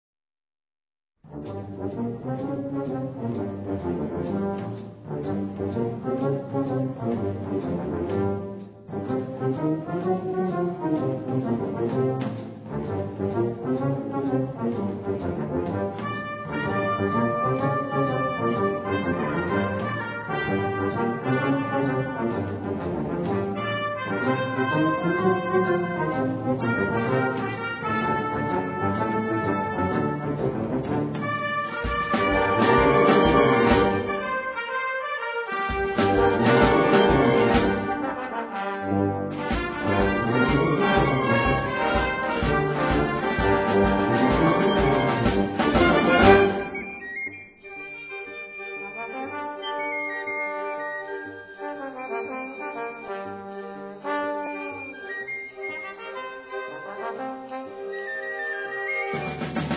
Gattung: Swing-Marsch
Besetzung: Blasorchester